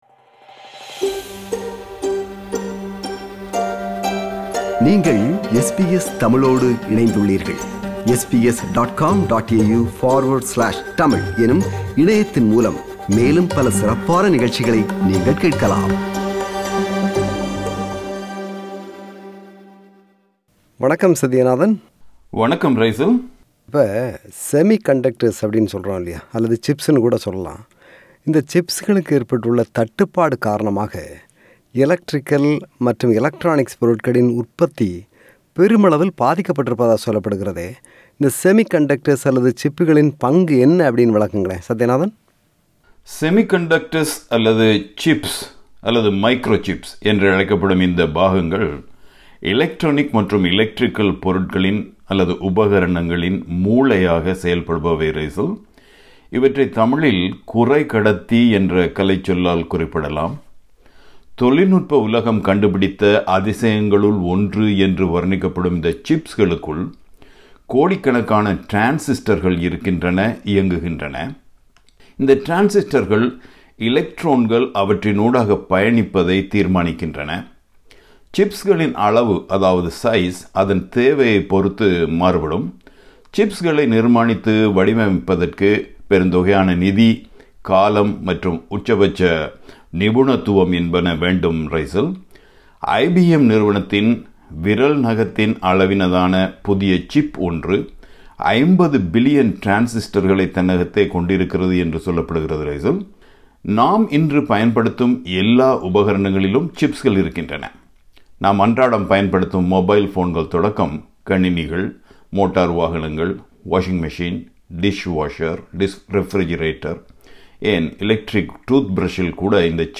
A global shortage of semiconductor chips is impacting all industry sectors, regions and likely a sizeable share of the world’s households. A veteran broadcaster